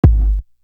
Marathon Kick.wav